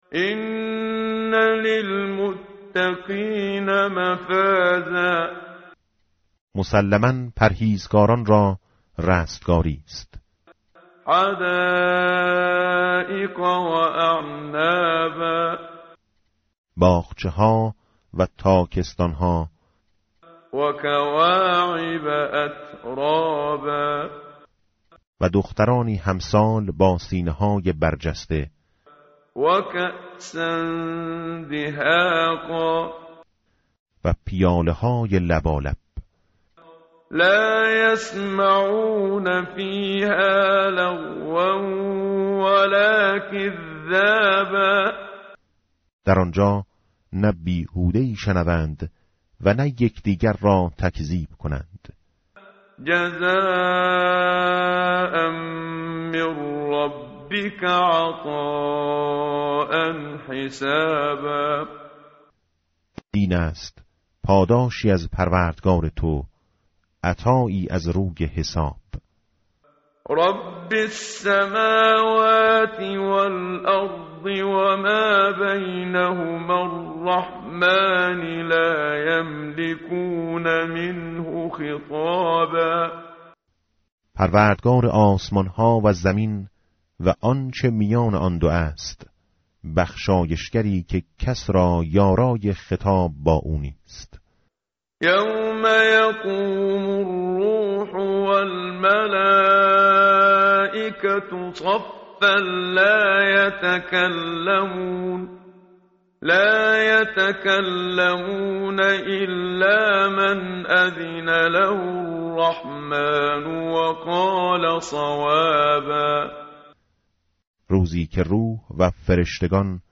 tartil_menshavi va tarjome_Page_583.mp3